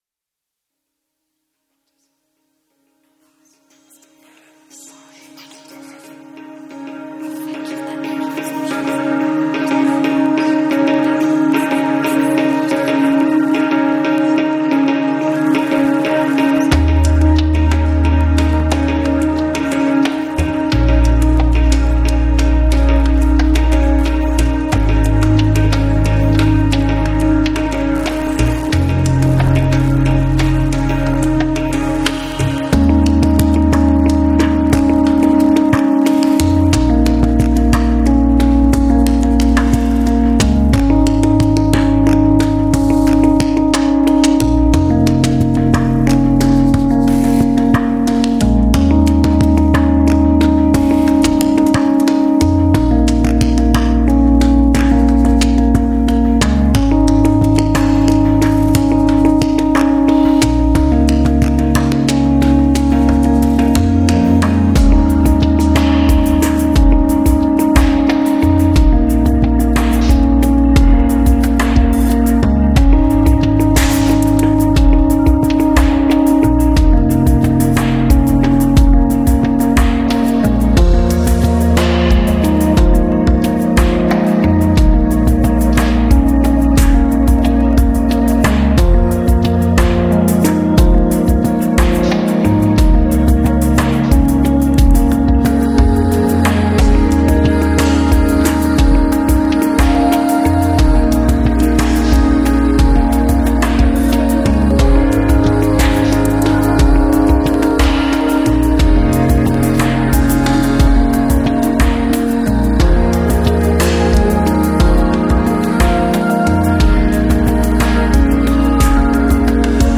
风格：华语, 摇滚